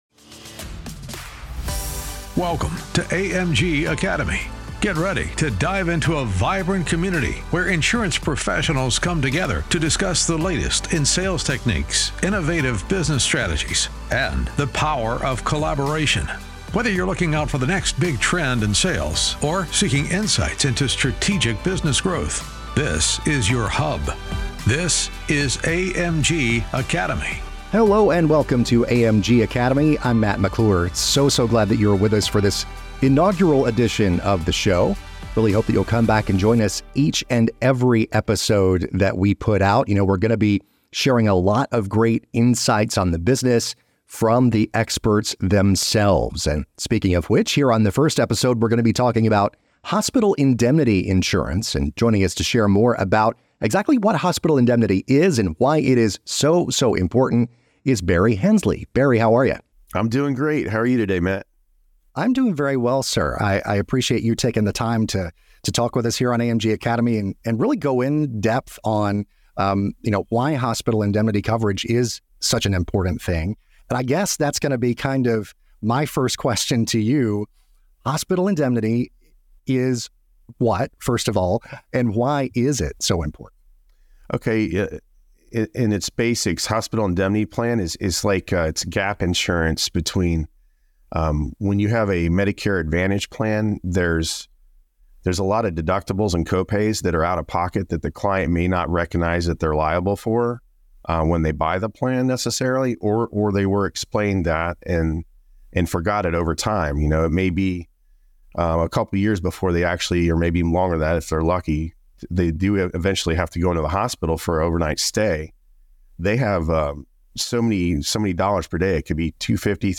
Who is an ideal client for this type of policy? What are some of the optional riders agents can offer? We go in-depth about everything you need to know in this wide-ranging conversation.